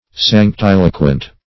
Search Result for " sanctiloquent" : The Collaborative International Dictionary of English v.0.48: Sanctiloquent \Sanc*til"o*quent\, a. [L. sanctus holy + loquens, p. pr. of loqui to speak.]
sanctiloquent.mp3